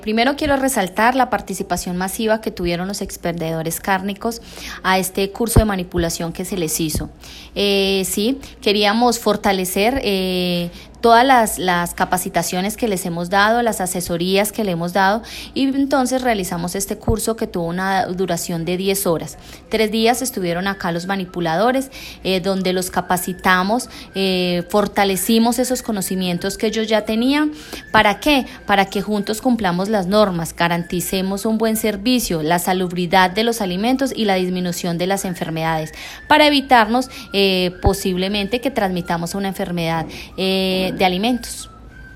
Comunicado-Audio-Secretaria-de-Salud-Bibiana-Romero.m4a